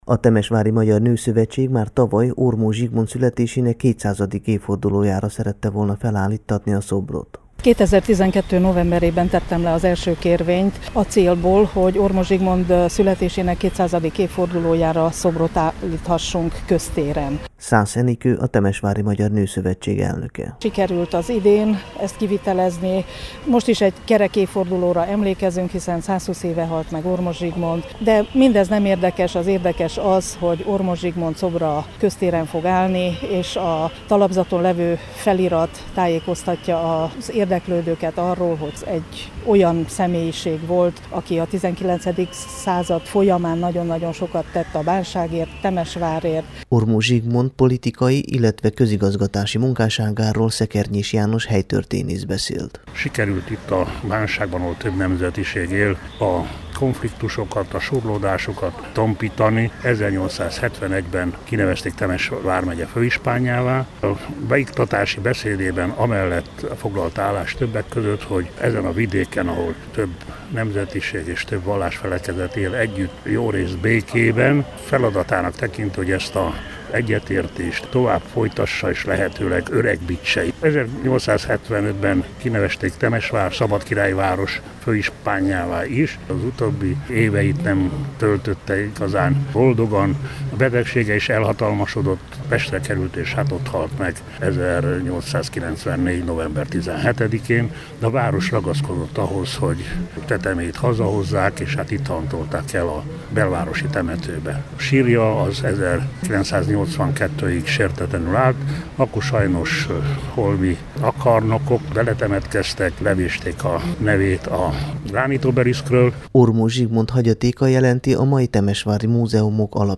a Bukaresti Rádió magyar adása számára készült tudósítása a rendezvényről.